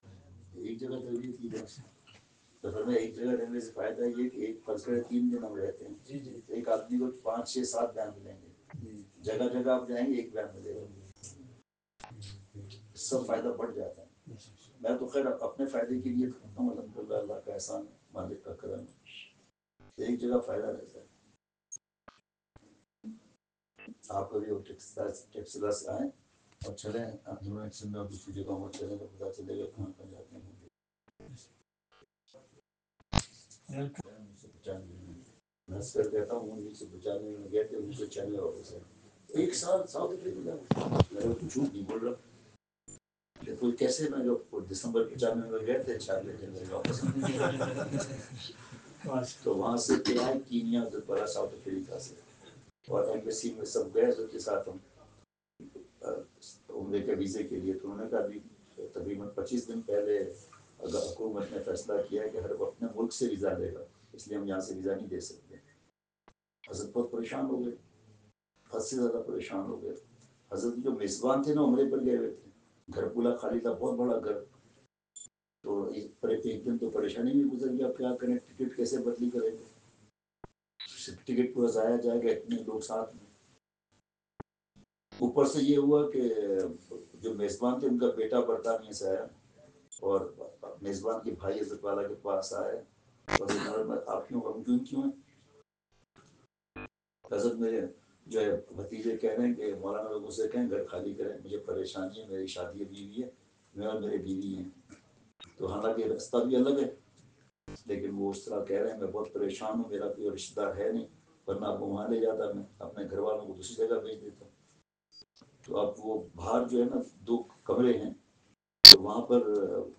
اصلاحی مجلس
بمقام۔رہائش گاہ ملٹی گارڈن بی ۱۷ اسلام آباد